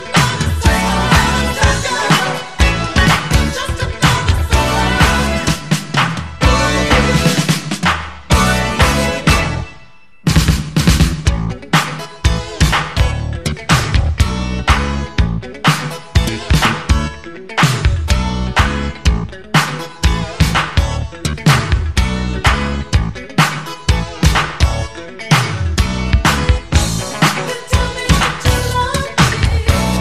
0 => "Rhythm'n'blues, soul"